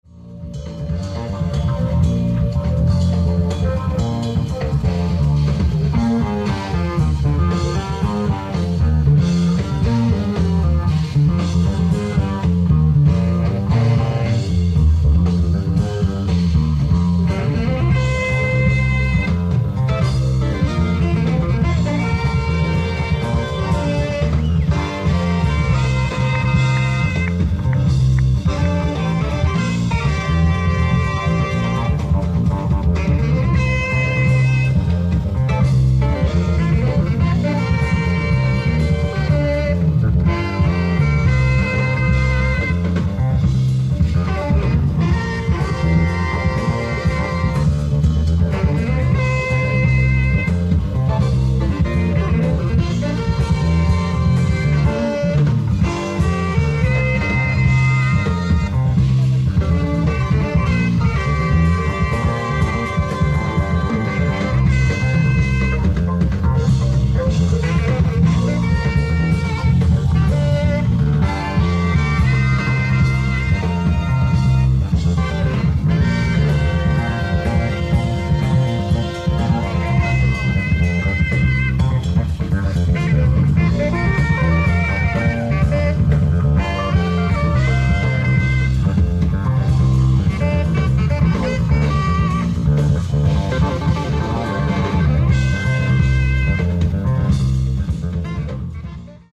ライブ・アット・アンティーブ、ジュアン・レ・パン、フランス